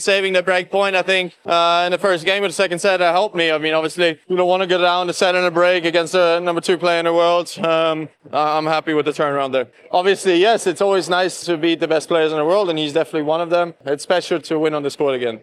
Zverev says his serve helped him a lot in the match against Alcaraz: